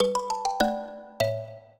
mbira
minuet14-7.wav